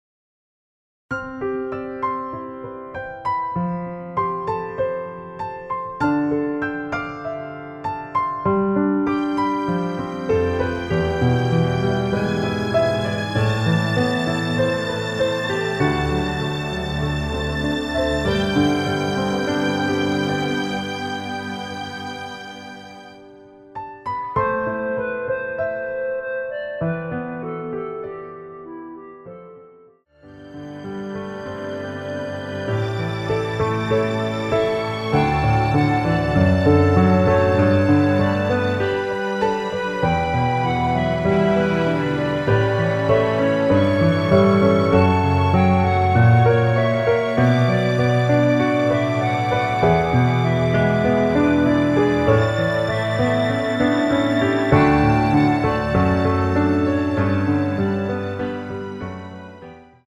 원키 멜로디 포함된 MR입니다.
Am
노래방에서 노래를 부르실때 노래 부분에 가이드 멜로디가 따라 나와서
앞부분30초, 뒷부분30초씩 편집해서 올려 드리고 있습니다.
중간에 음이 끈어지고 다시 나오는 이유는